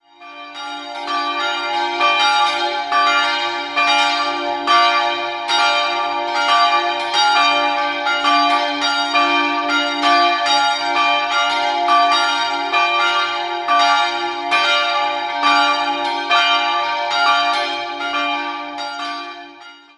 Im Inneren eine schlichte barocke Ausstattung. 3-stimmiges TeDeum-Geläute: d''-f''-g'' Alle drei Glocken stammen aus dem Jahr 1971 und wurden von der Gießerei Heidelberg hergestellt.